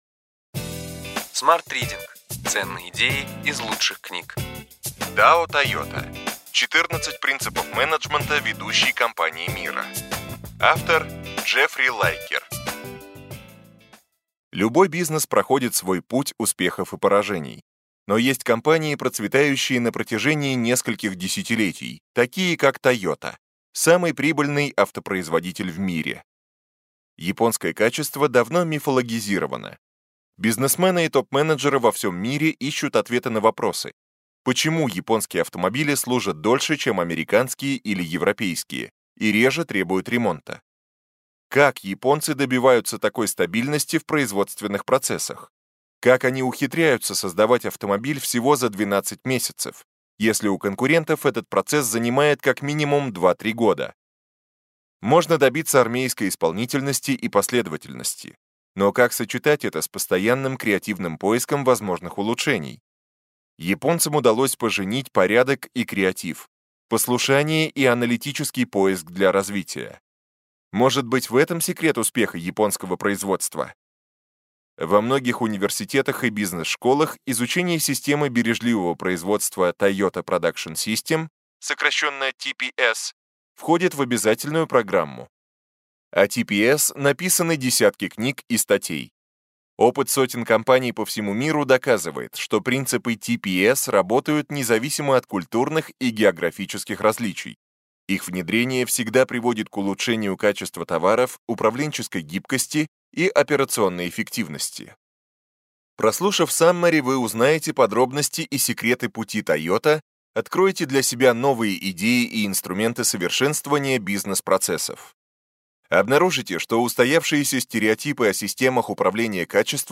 Аудиокнига Ключевые идеи книги: Дао Toyota. 14 принципов менеджмента ведущей компании мира.